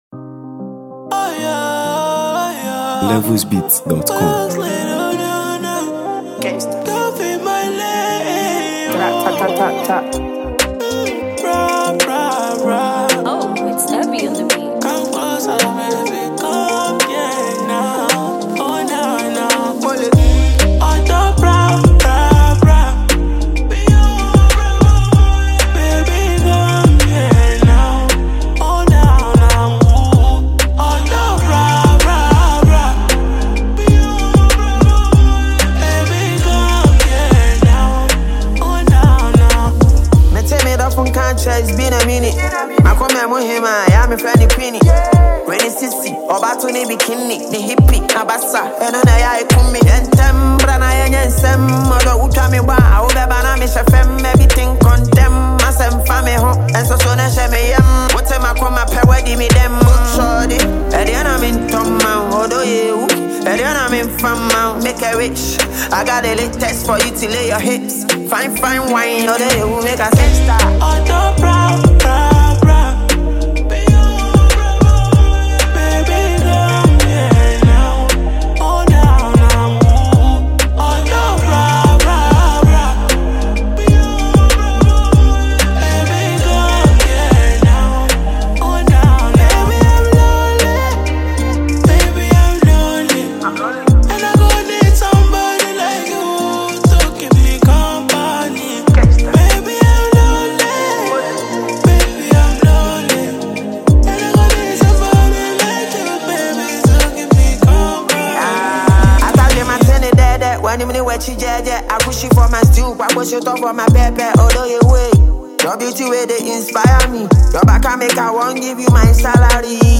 Song
all wrapped in a melodious Afrobeat-inspired sound.